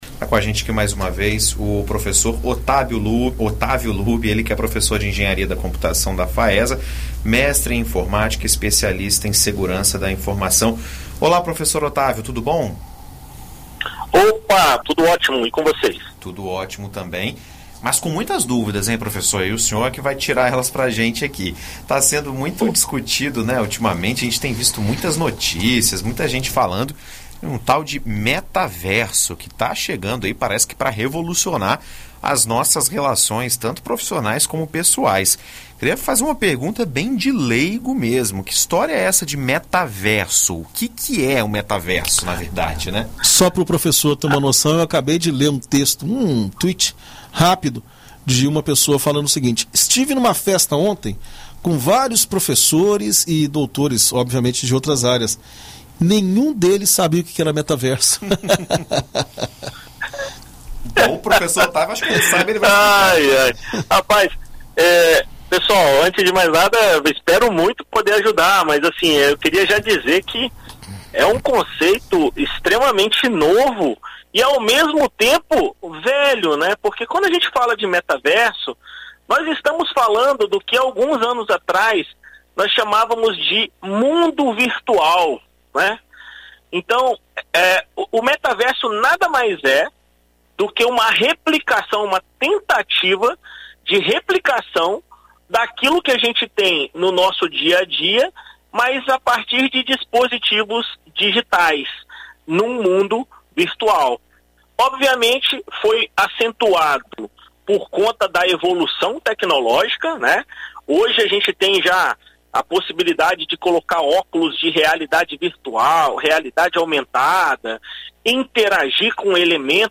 Em entrevista à BandNews FM Espírito Santo nesta terça-feira